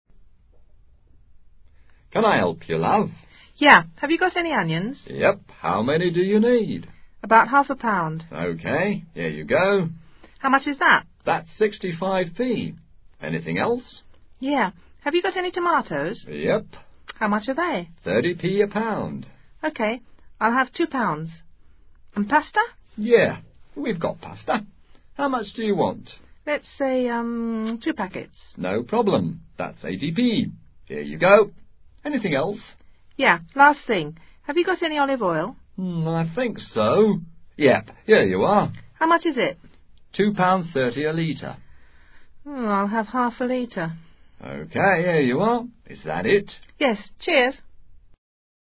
Diálogo entre una mujer y un almacenero.